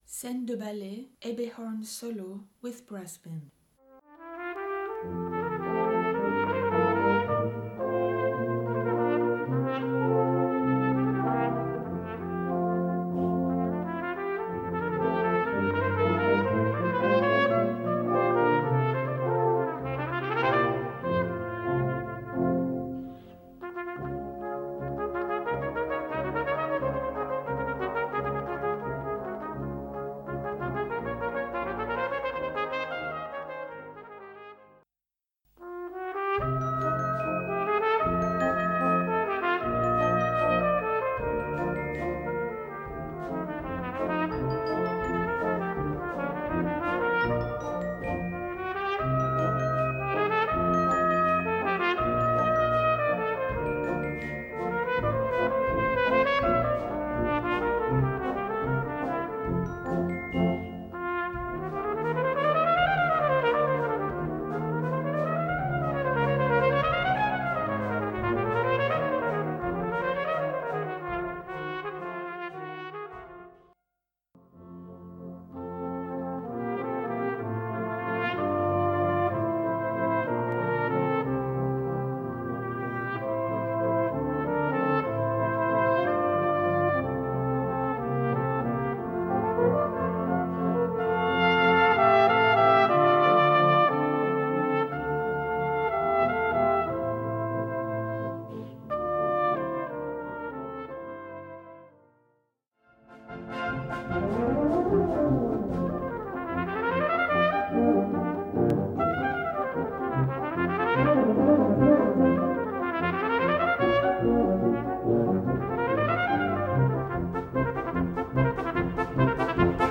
Brass Band
Solo & Brass Band